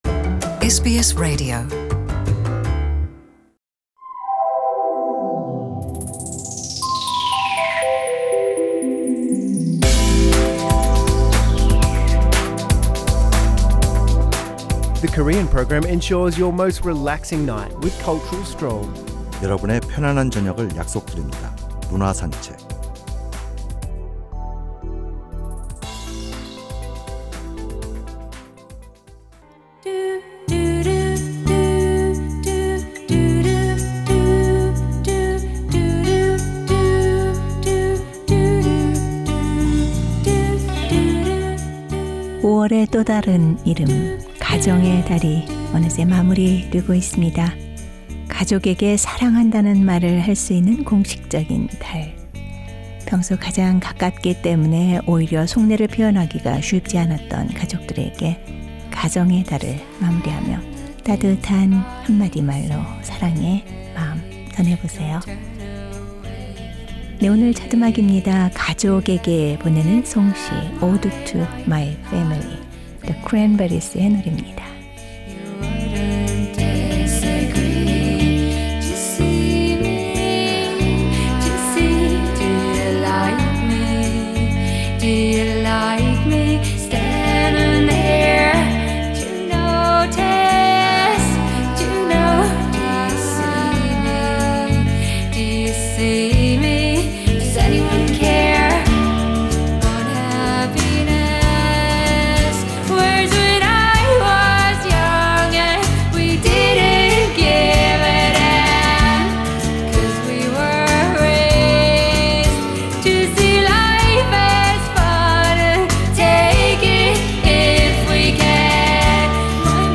The Weekly Culture Odyssey looks into arts and artists' life with background music, and presents a variety of information on culture, which will refresh and infuse with intellectual richness.